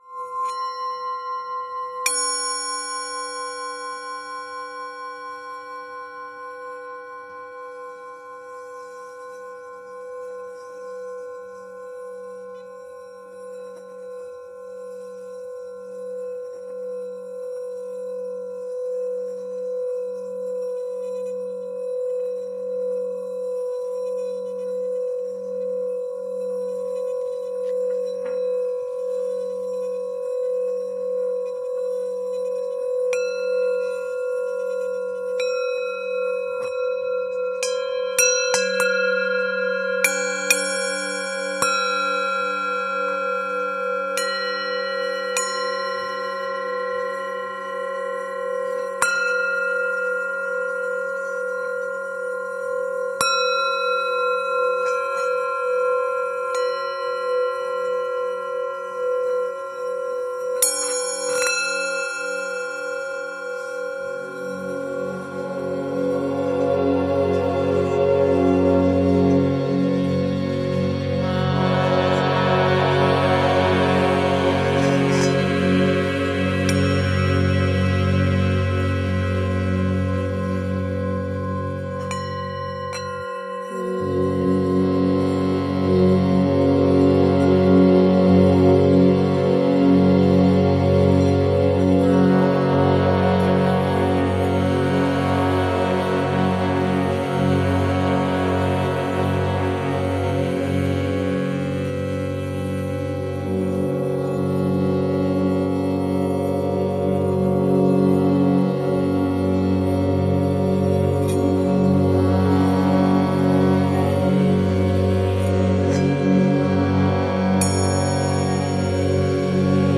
played the bowls
in a tape of his yoga teacher chanting.  A bunch of sample manipulation